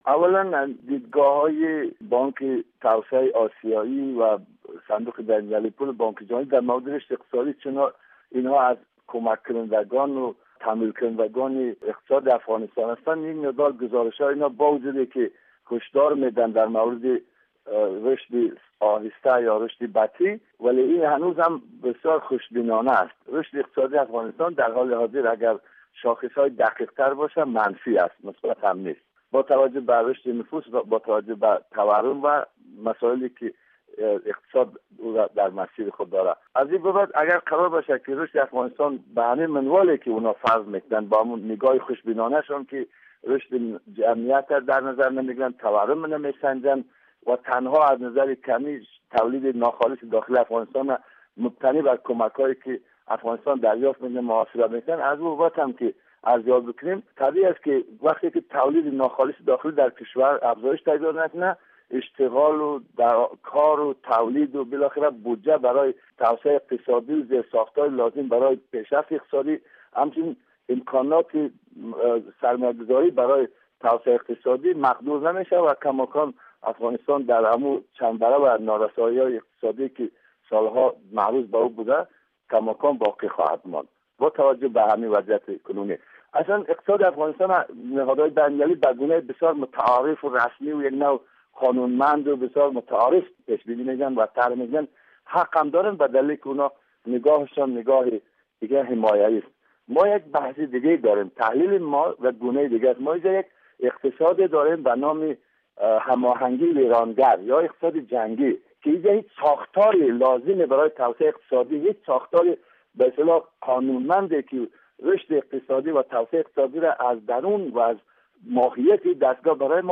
مصاحبۀ کامل